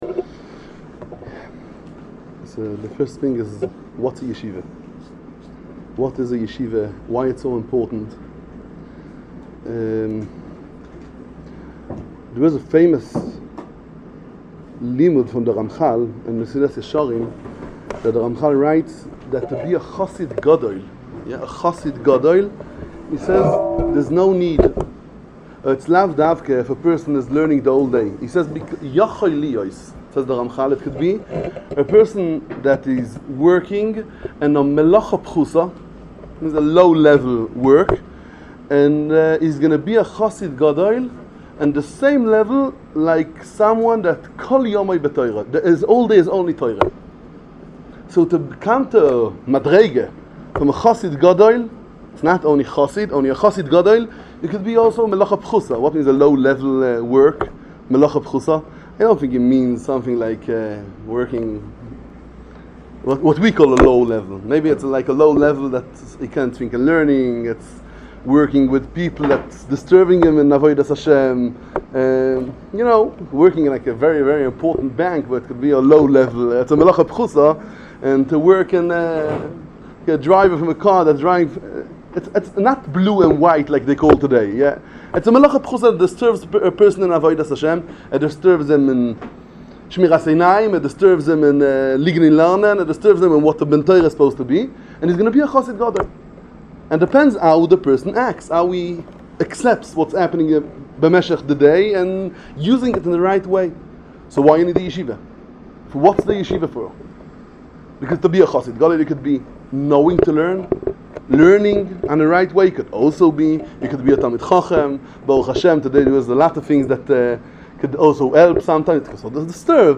Why to learn in A yeshiva? Speech